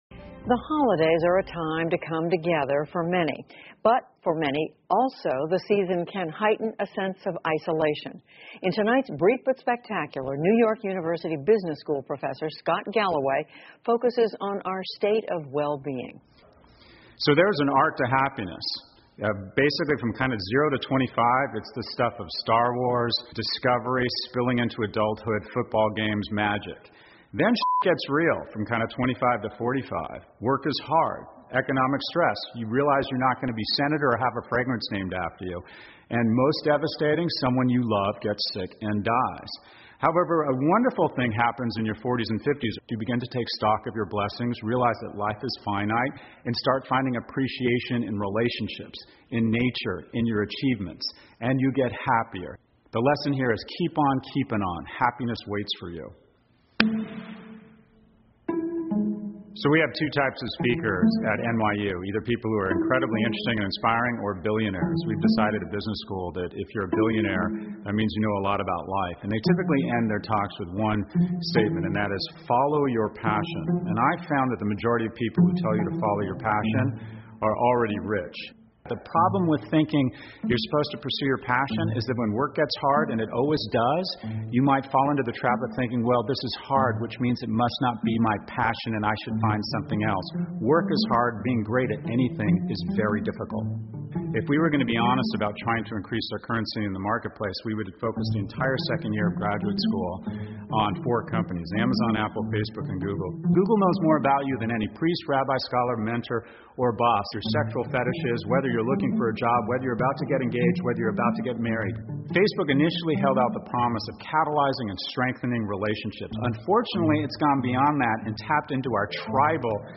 PBS高端访谈:斯科特·加洛威的幸福哲学 听力文件下载—在线英语听力室